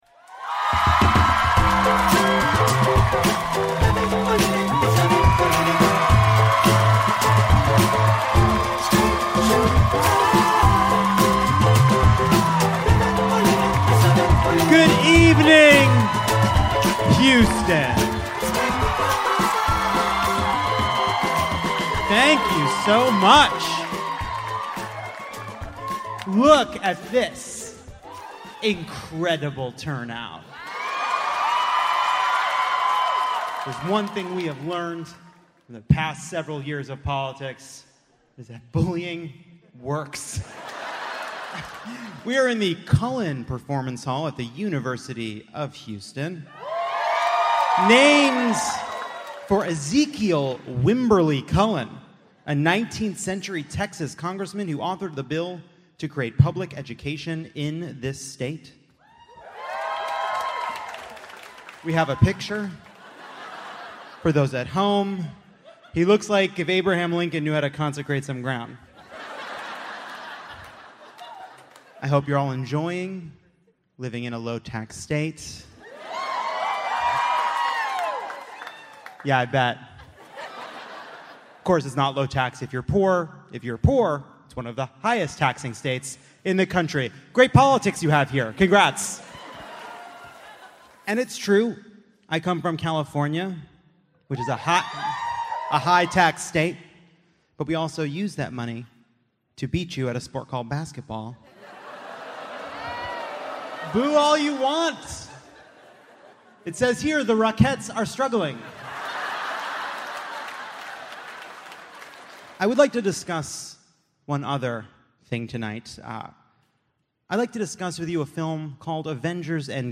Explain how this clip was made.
Democracy: Endgame (LIVE from Houston)